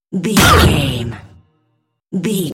Cinematic stab hit trailer
Sound Effects
Atonal
heavy
intense
dark
aggressive